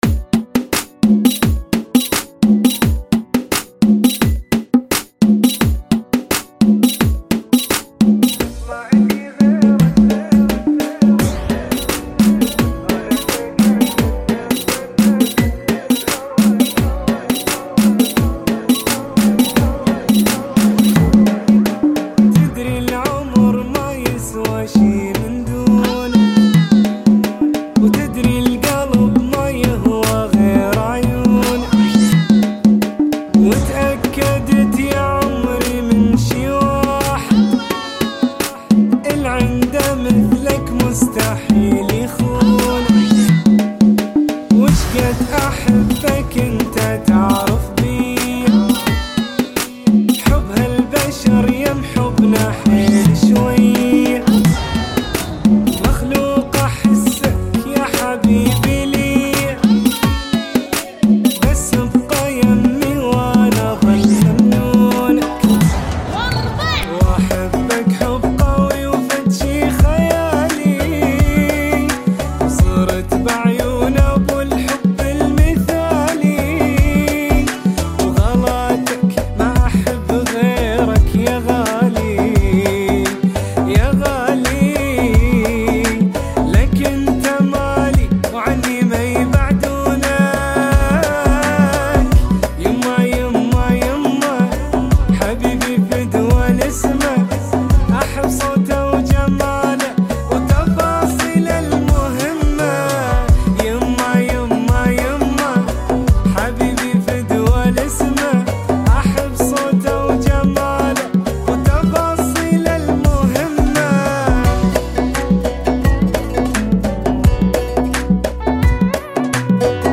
86 Bpm